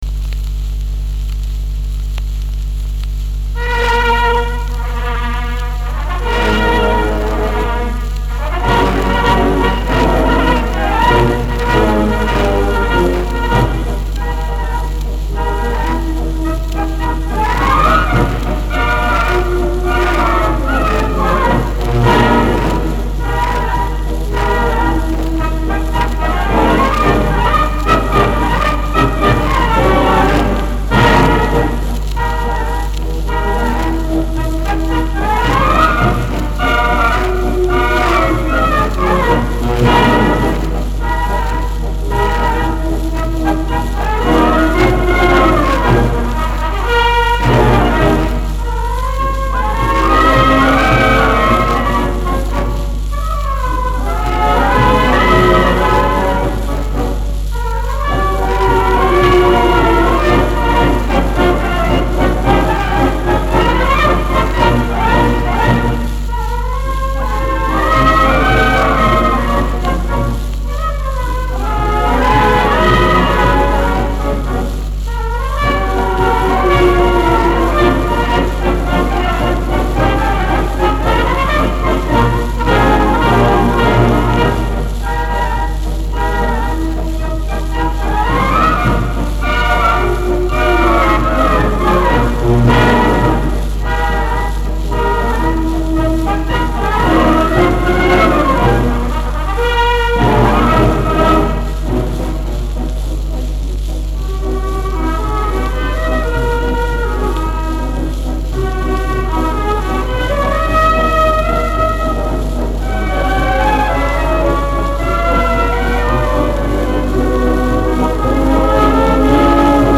Марши